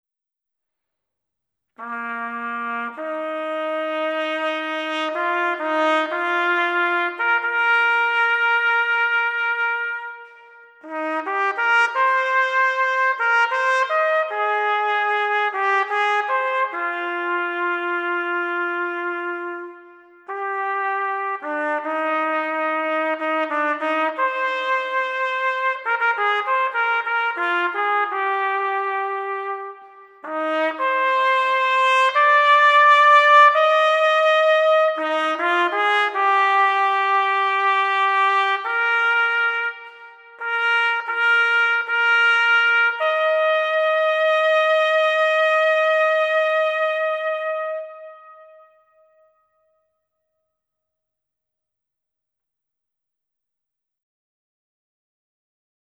hejnal_swidwina.mp3